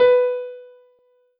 piano-ff-51.wav